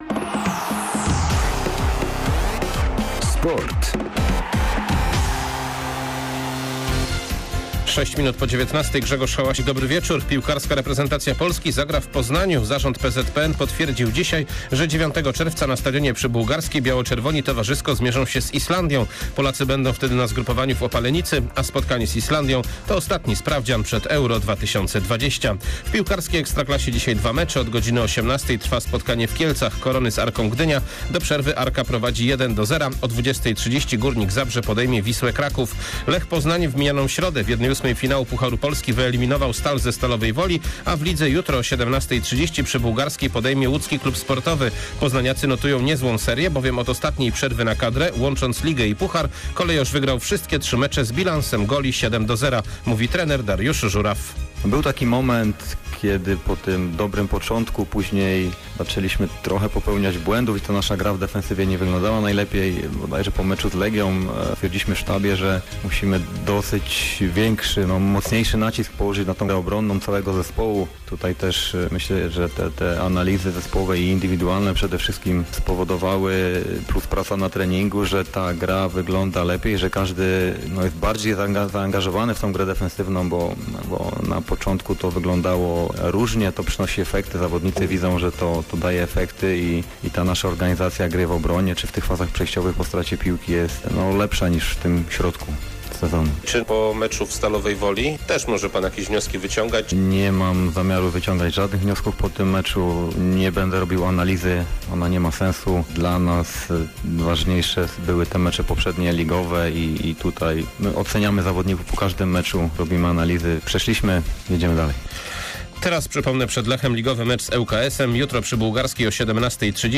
06.12. SERWIS SPORTOWY GODZ. 19:05